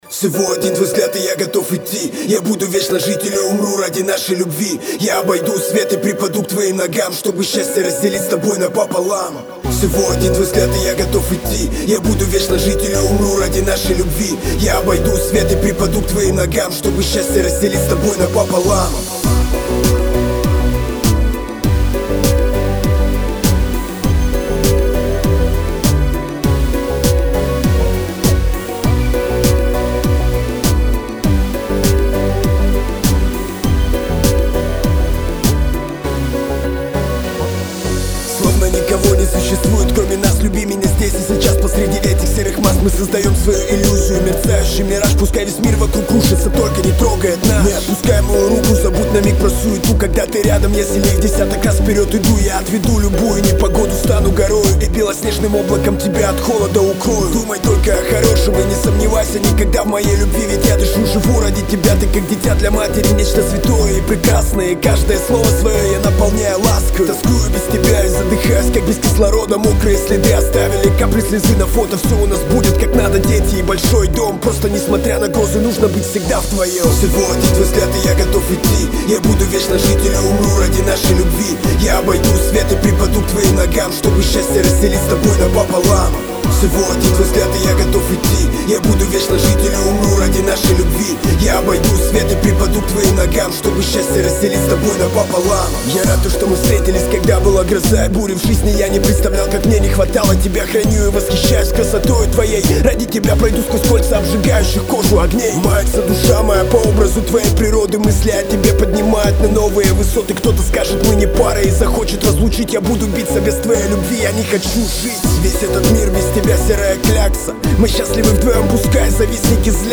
Категория: RAP, R&B